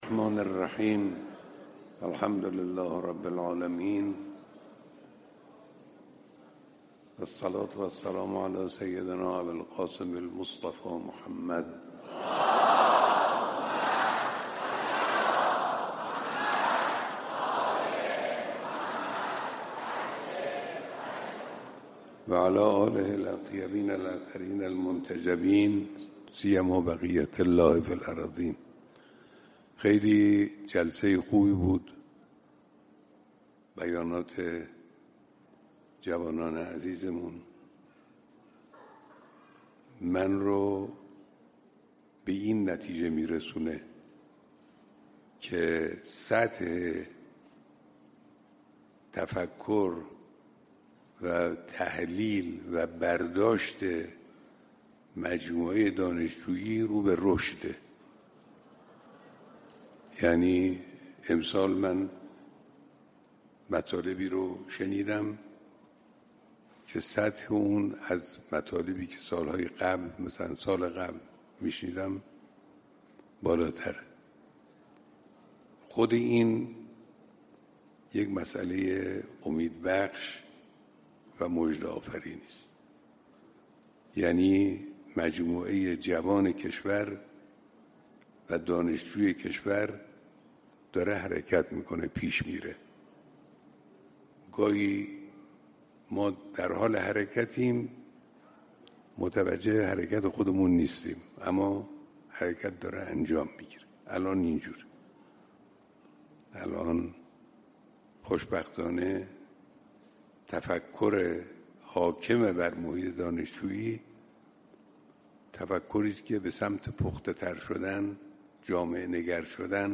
صوت/ بیانات رهبر انقلاب در دیدار دانشجویان